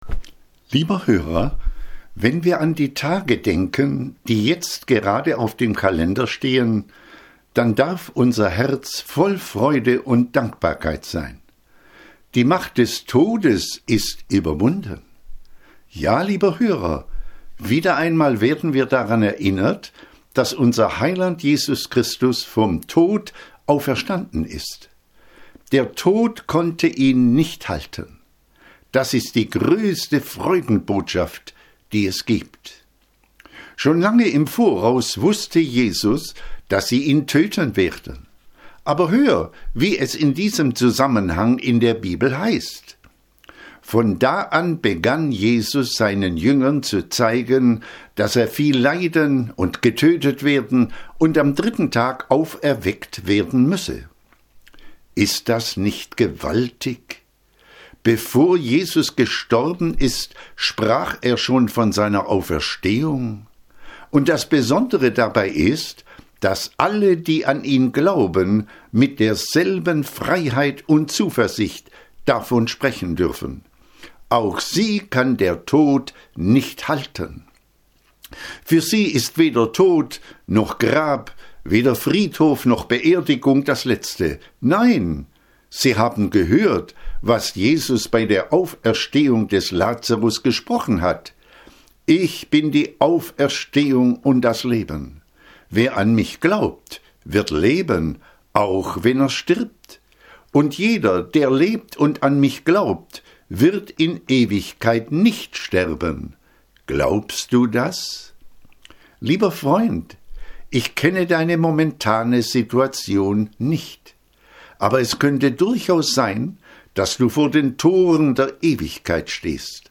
KURZPREDIGT DER WOCHE